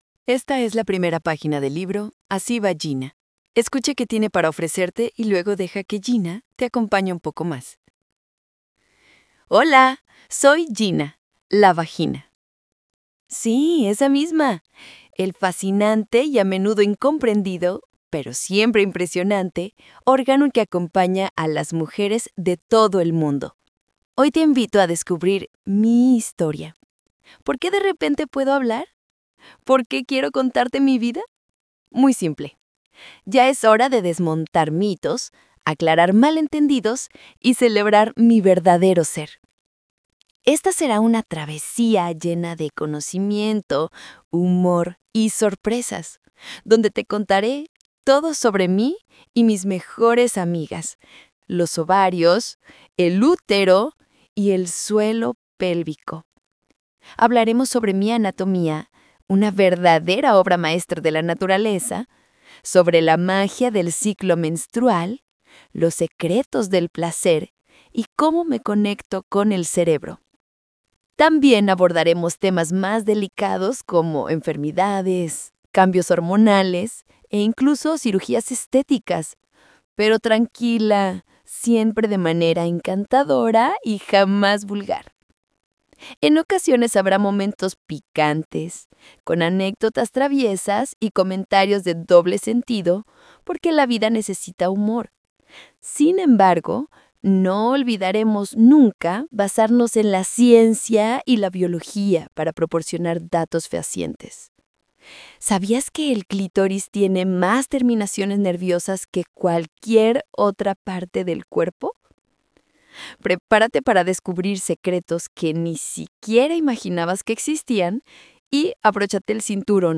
Readings (audio)